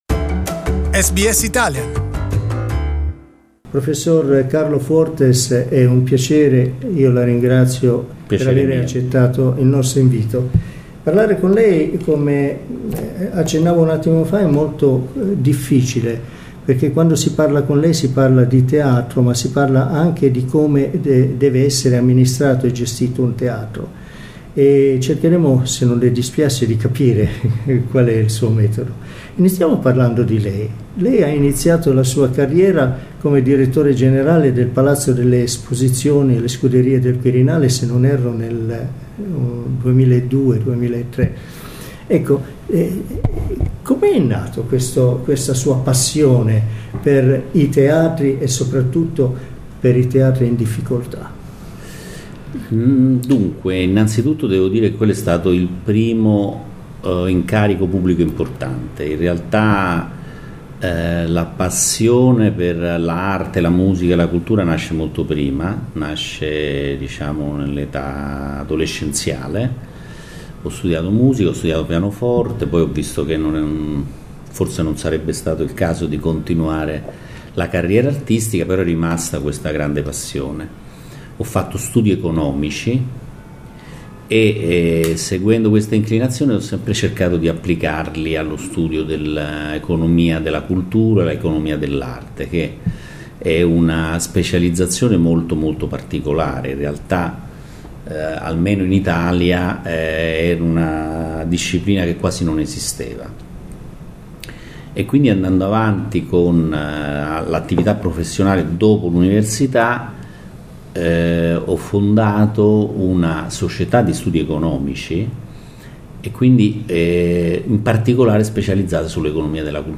In questa intervista, spiega come è riuscito e rimettere in sesto le sorti finanziarie di alcuni dei più famosi teatri italiani.